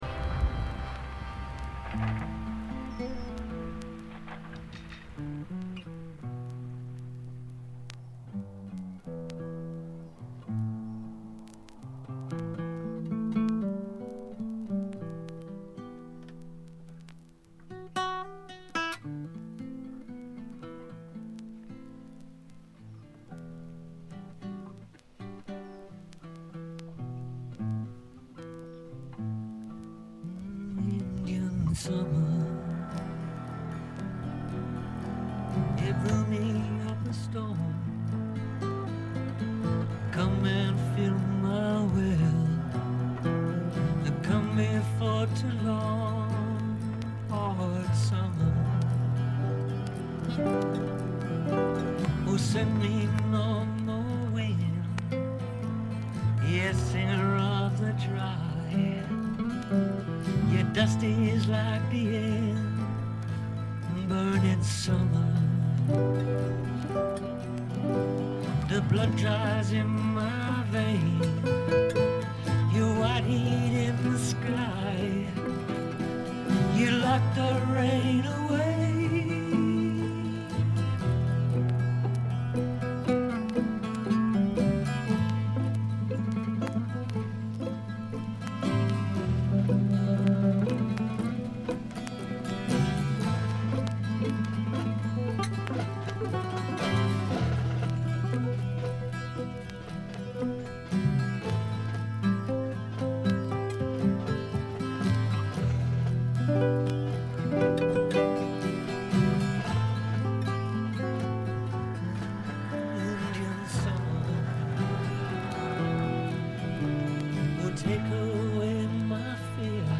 試聴曲は現品からの取り込み音源です。
guitar, mandolin, fiddle, keyboards, harmonica, vocals
violin, keyboards, harp, whistle
drums